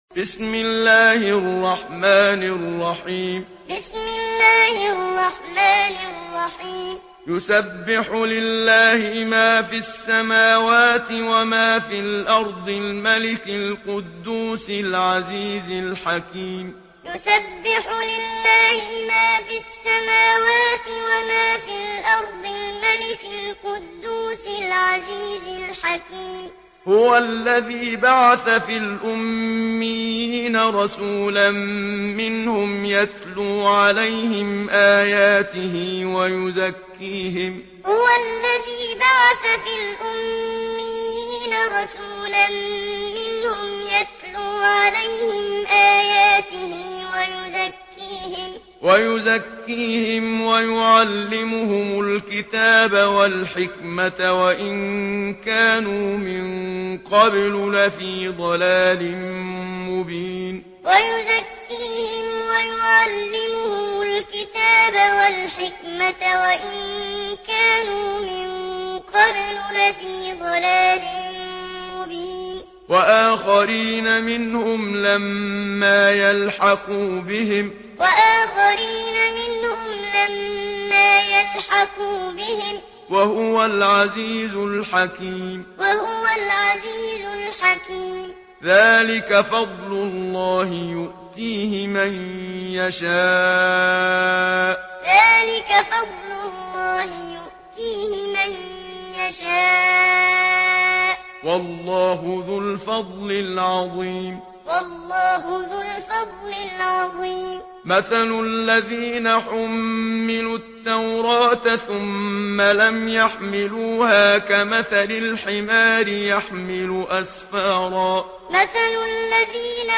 Surah আল-জুমু‘আ MP3 by Muhammad Siddiq Minshawi Muallim in Hafs An Asim narration.